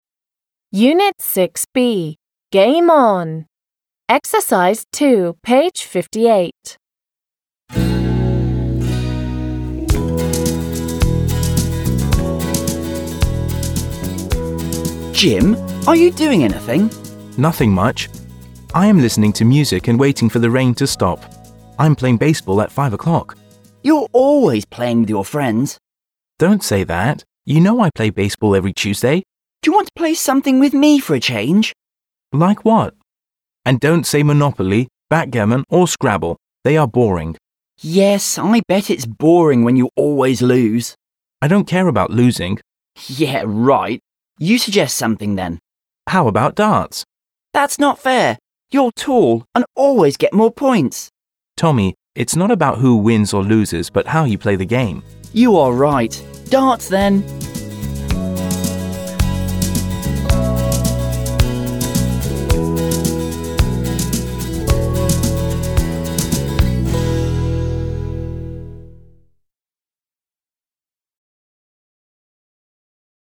Monopoly, backgammon, Scrabble, and darts are mentioned in the dialogue. − В диалоге упоминаются монополия, нарды, эрудит, дартс.